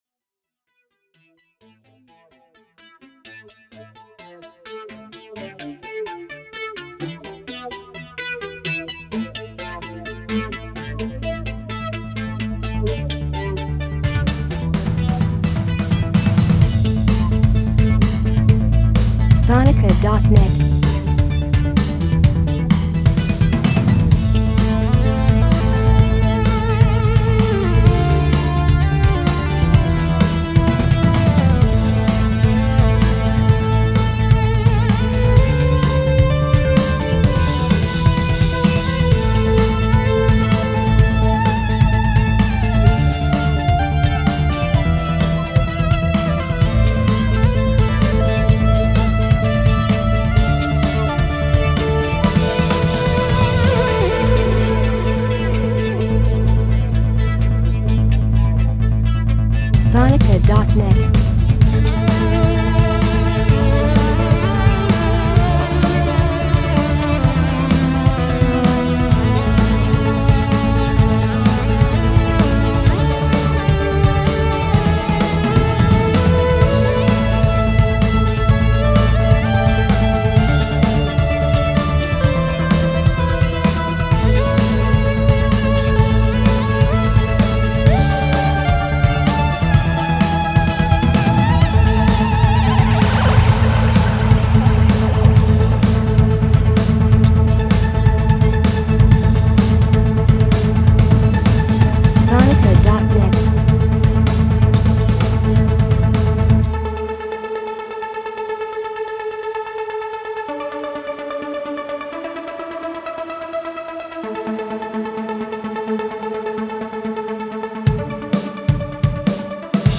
new live-studio versions